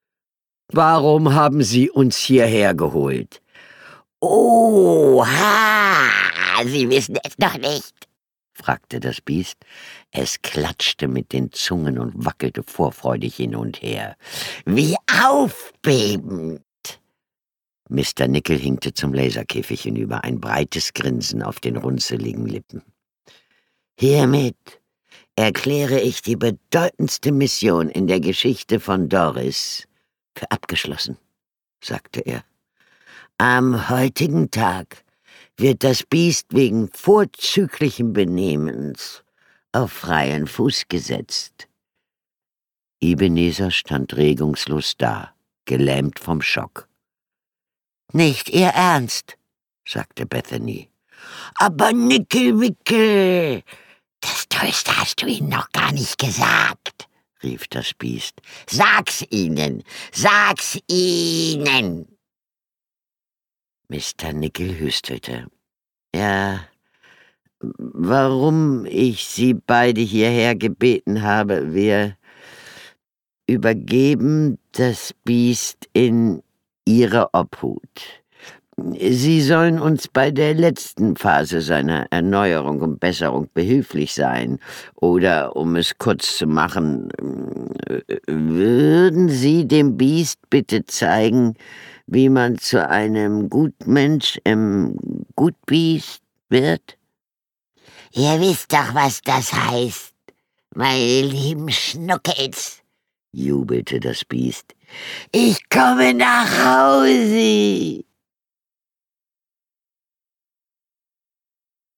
Jack Meggitt-Phillips (Autor) Mechthild Grossmann (Sprecher) Audio-CD 2022 | 1.